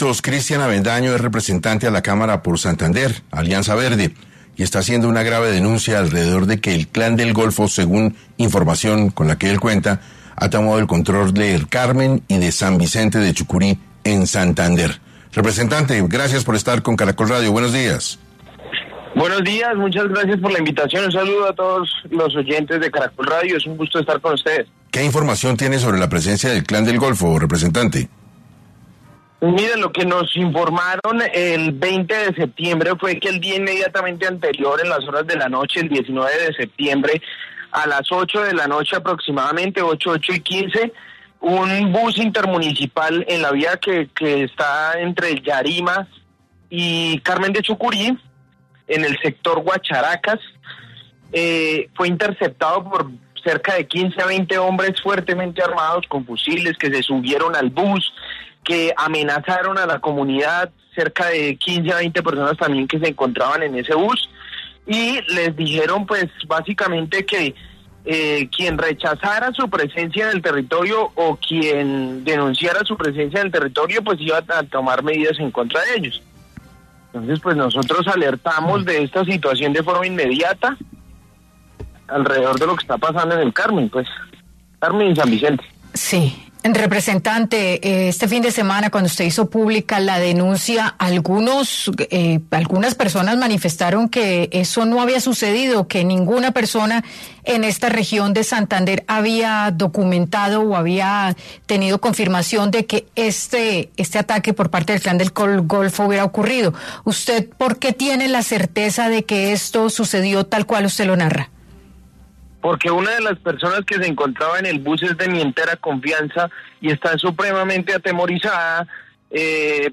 En 6AM habló Cristian Avendaño, representante a la Cámara por Santander, sobre la presencia del Clan del Golfo en Carmen de Chucurí, luego de que, hombres armados se tomaran un bus
Cristian Avendaño, representante a la Cámara por Santander, Alianza verde, estuvo este lunes 23 de septiembre, en el programa 6AM de Caracol Radio, hablando sobre la denuncia impuesta por la presencia del Clan del Golfo, en Carmen de Chucurí, luego de haberse tomado un bus.